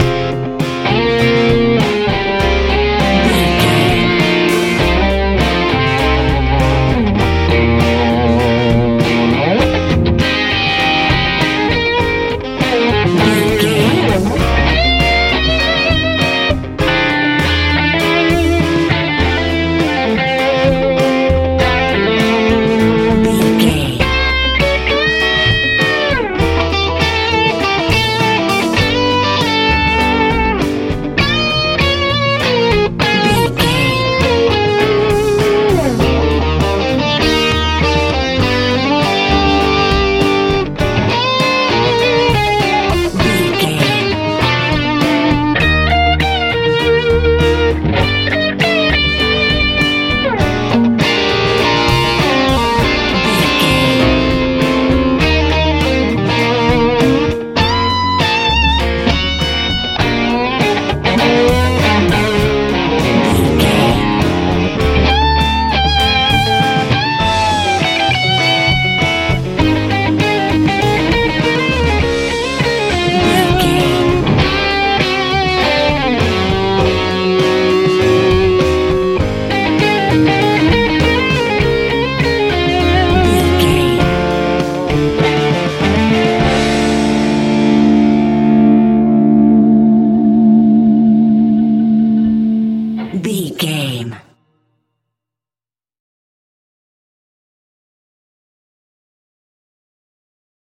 Ionian/Major
G♯
tension
dramatic
drums
bass guitar
piano
electric guitar
suspense